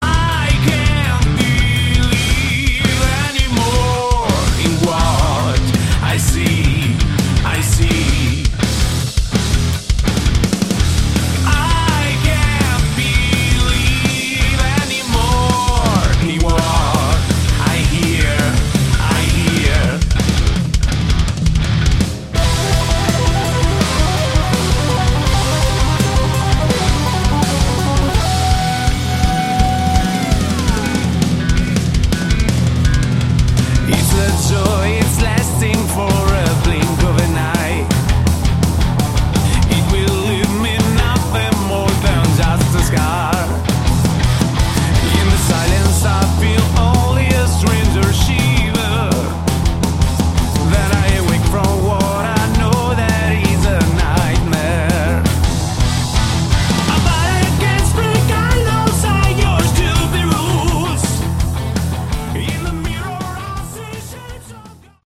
Category: Melodic Prog Rock
vocals
guitars
keyboards
bass
drums